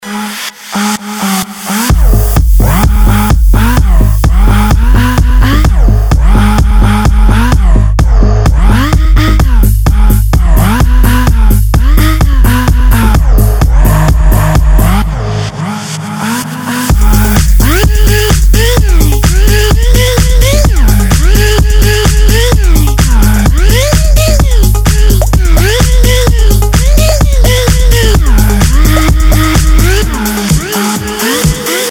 • Качество: 192, Stereo